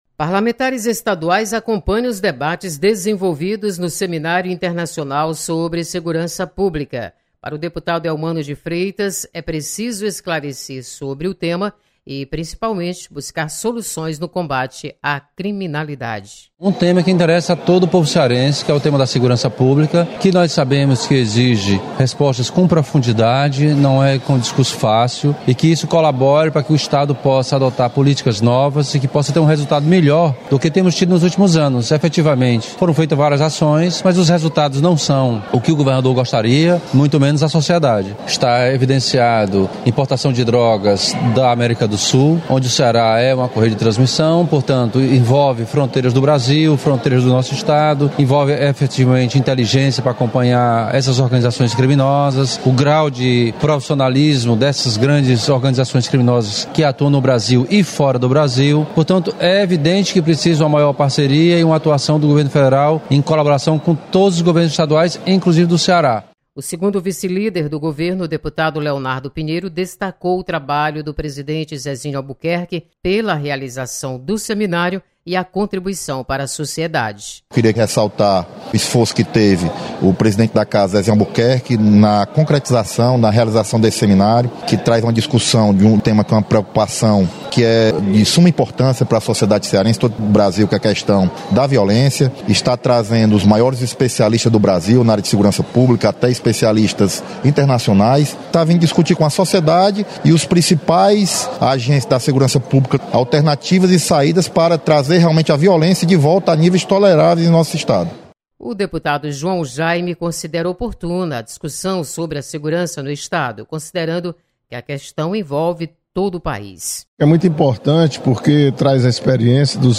Deputados comentam sobre debates do Seminário Internacional de Segurança Pública.